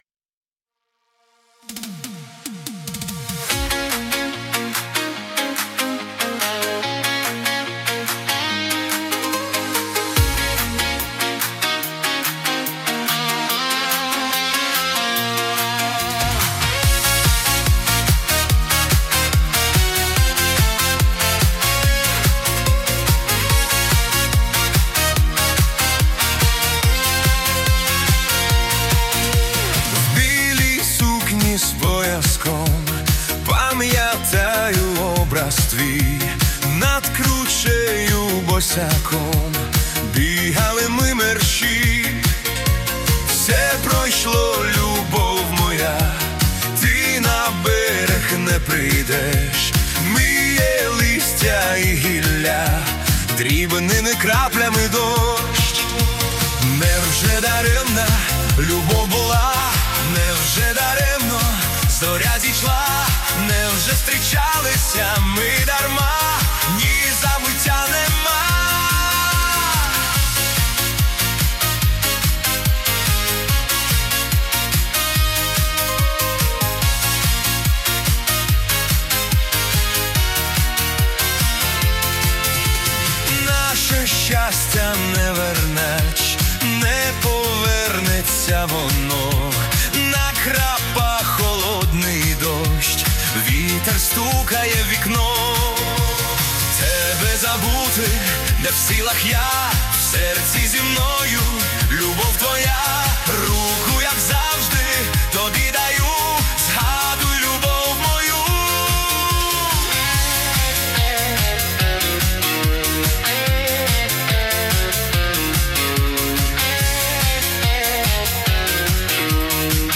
Modern Ukrainian Estrada
Стиль: Upbeat, Punchy Drums, Retro Vibe